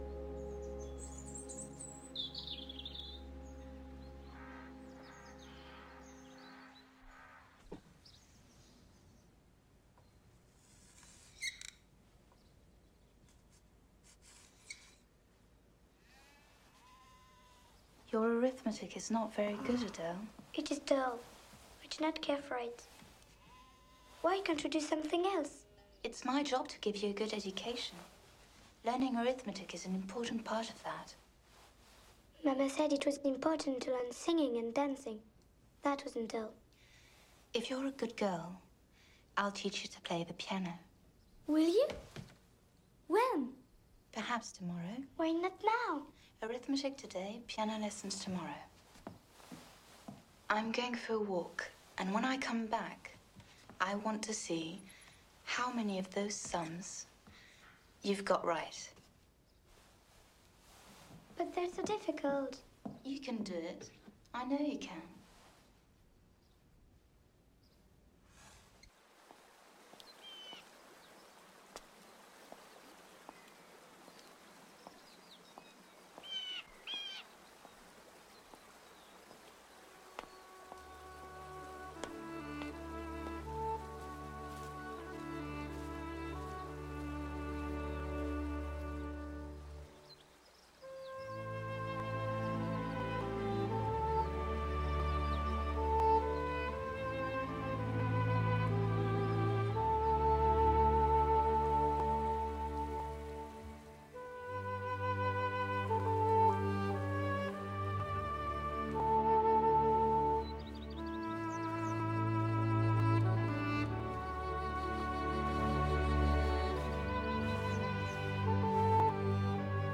Also this scene was already in my collection but it is now in better quality.